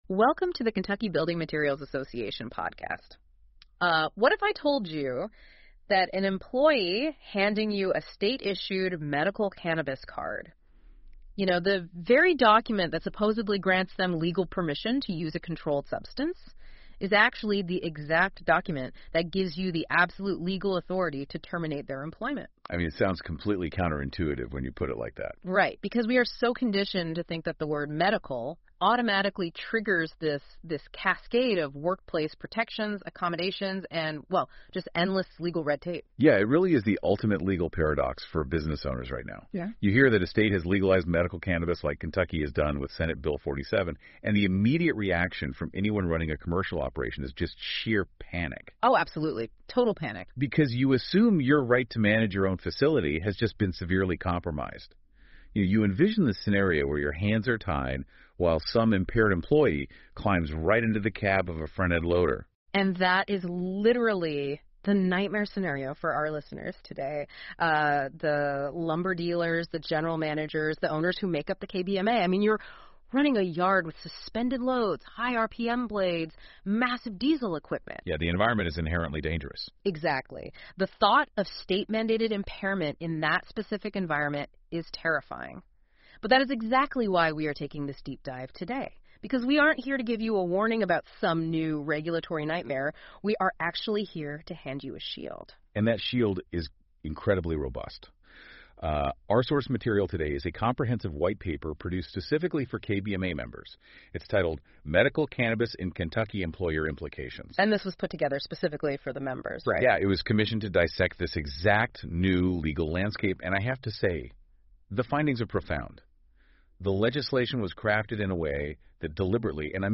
The KBMA Podcast delivers monthly conversations designed specifically for our members, covering the latest industry news, legislative updates, and regulatory changes at both the state and federal level.